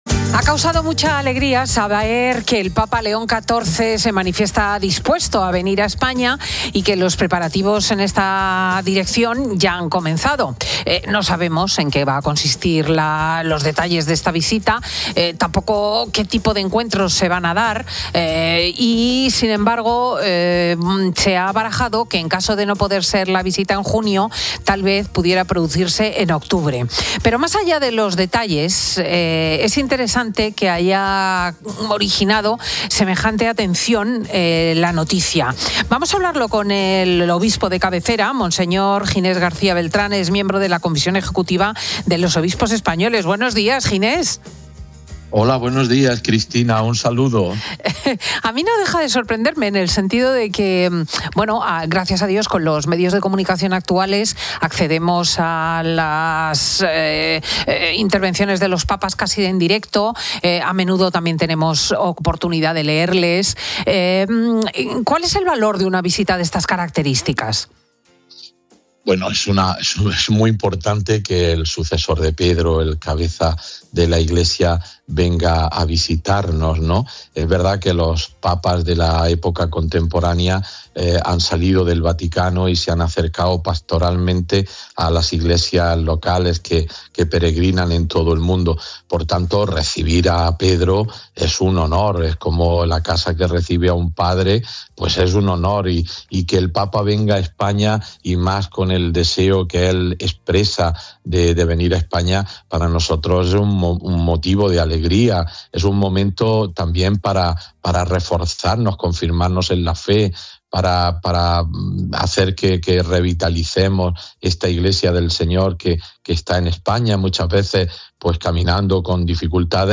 El obispo de Getafe, Ginés García Beltrán, desvela en COPE que el pontífice tiene un "verdadero deseo" de venir y podría visitar Madrid, Barcelona y Canarias